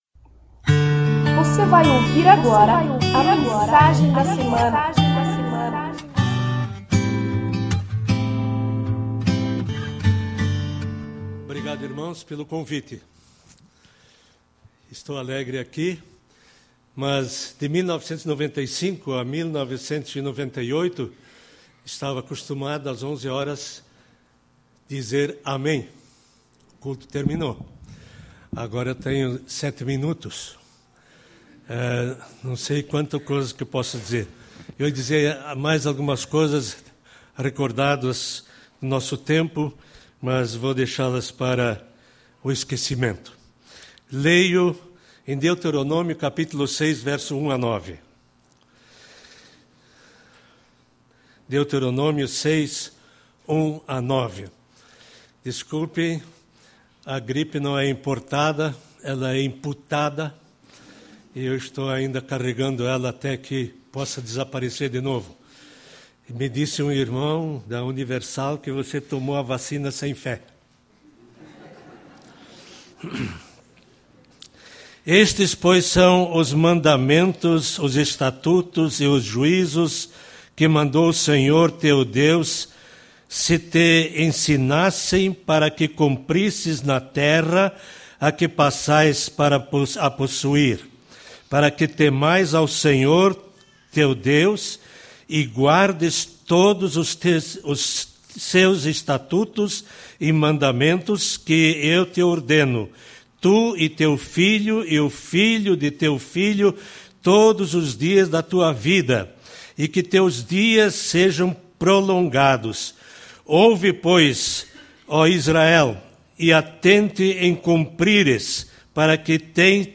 Esta é a primeira mensagem da Série Família Saudável, que acontece no mês de Agosto na IEMAV.